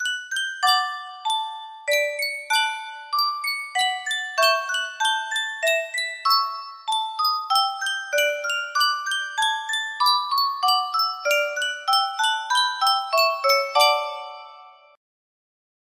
Yunsheng Spieluhr - Mozart Eine Kleine Nachtmusik 6626 music box melody
Full range 60